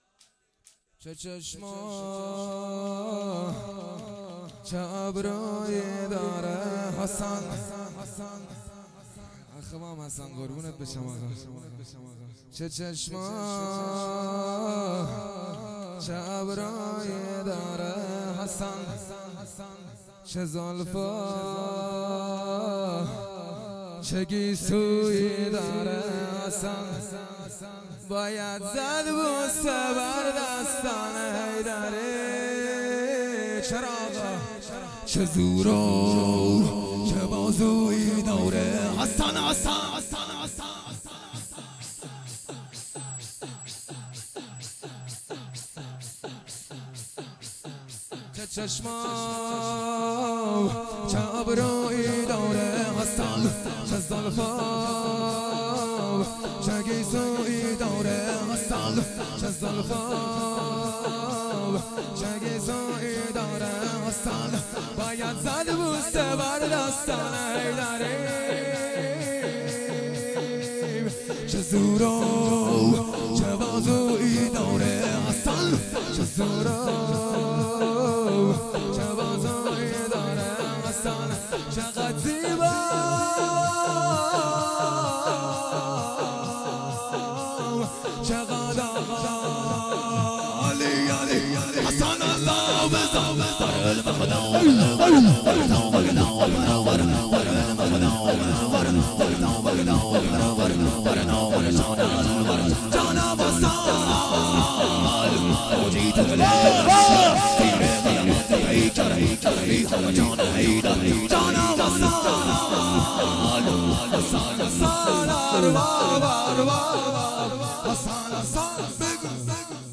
شور
شهادت حضرت زهرا ۷۵ روز ۱۳۹۸